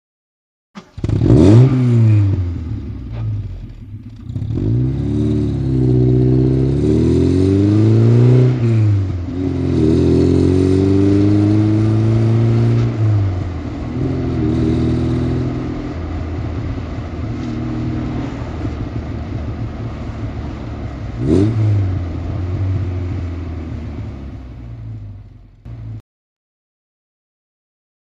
Automobile; Interior ( Constant ); Interior Alfa Romeo Start Up And Away, Then Stops, Then Away Again Up Gears And Down Gears, Eventually Stops And Idling.